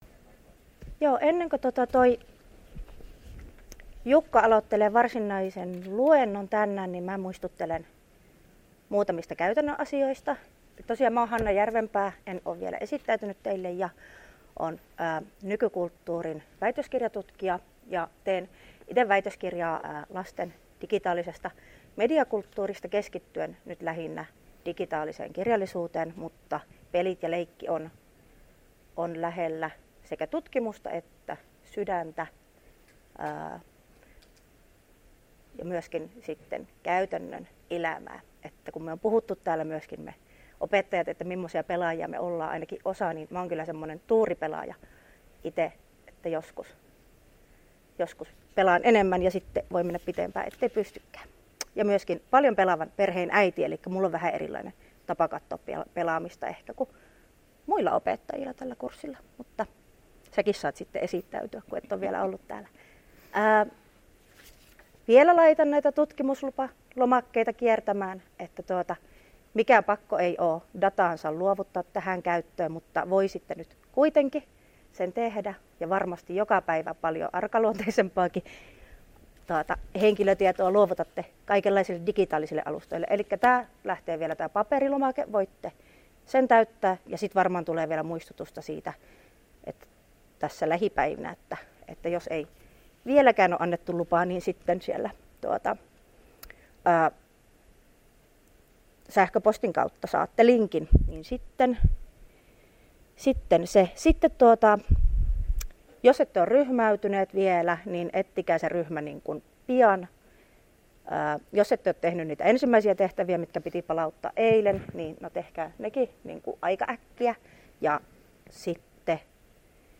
Luento 6.9.2022 — Moniviestin